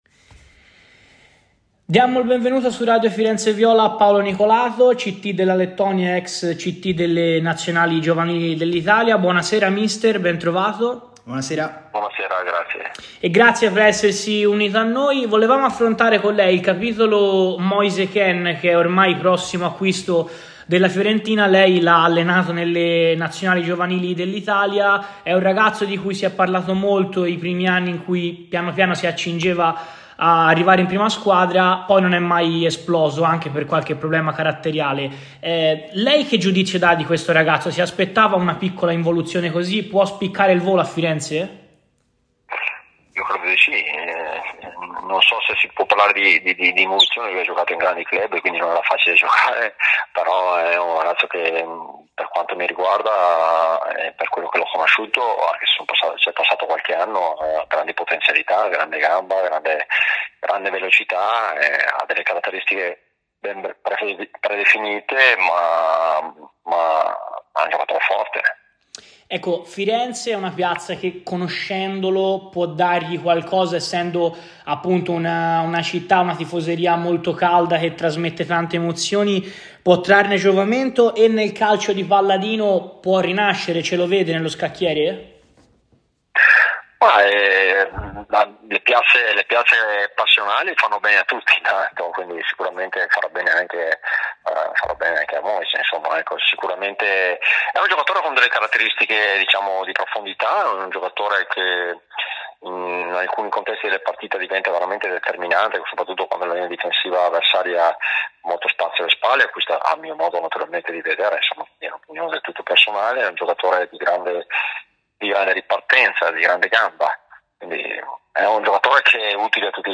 Il Commisario Tecnico della Lettonia ed ex selezionatore delle nazionali giovanili italiane, dall'Under 18 all'Under 21, Paolo Nicolato è intervenuto a Radio FirenzeViola durante la trasmissione "Viola Weekend" per parlare di vari calciatori viola allenati in azzurro dal C.T.